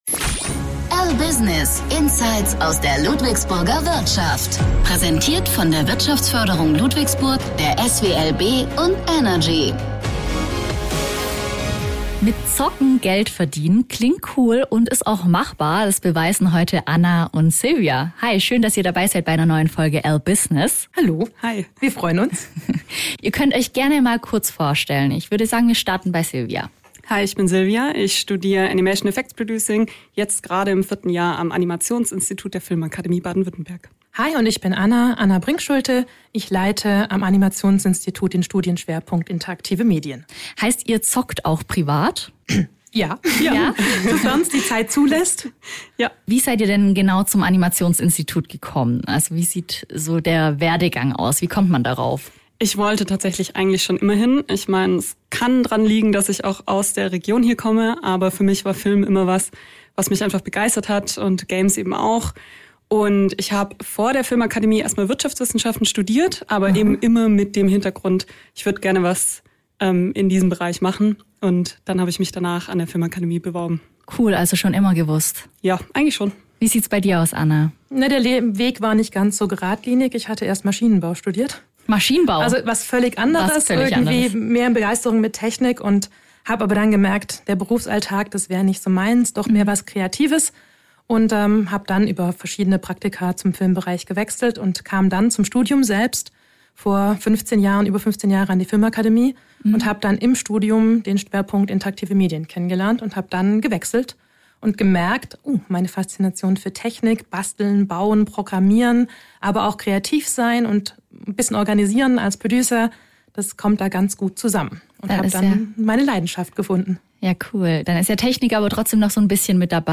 Zwei Games-Expertinnen zu Gast im LBusiness Podcast